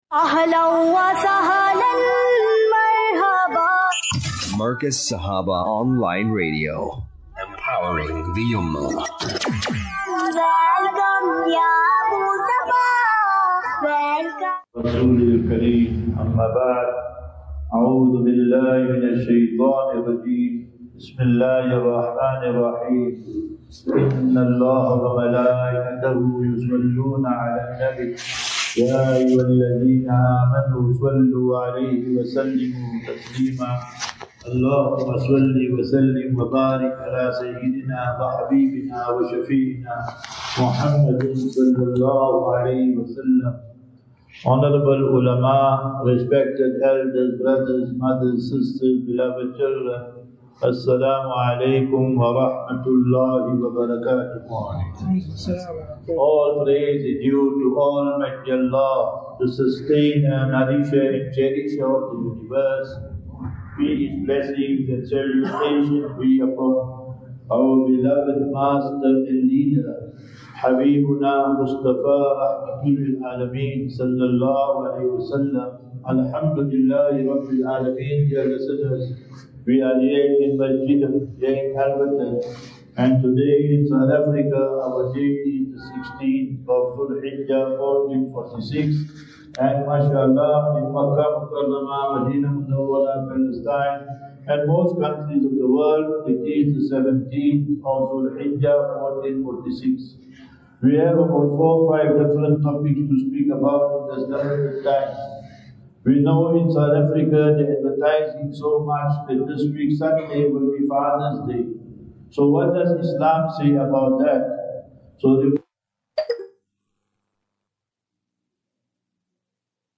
13 Jun 13 June 25 - Jumu,ah Lecture at Alberton Jumma Masjid
Jumu,ah Lecture